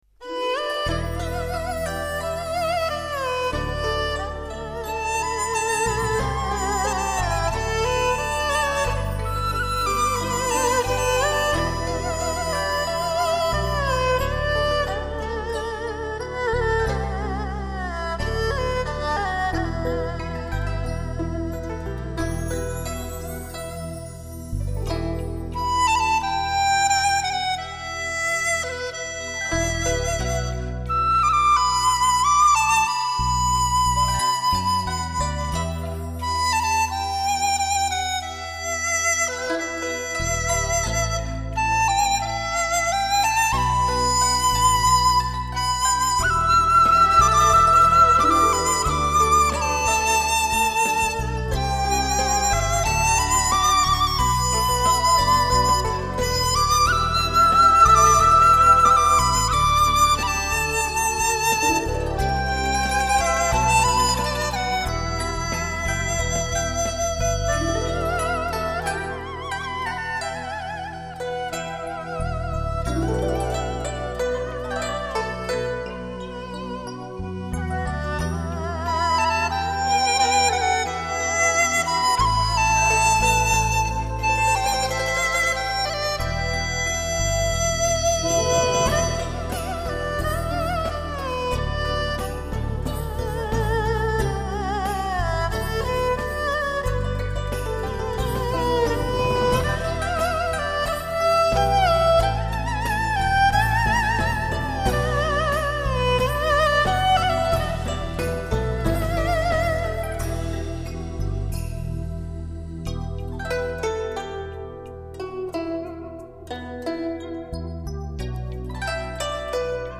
如怨如慕 如泣如诉 余音袅袅 箫韵悠悠 不绝如缕
笛声悠扬 箫声悠远 曲有尽意无穷
典范之声 300平米SSl录音棚完美收录
竹笛 箫演绎动人音乐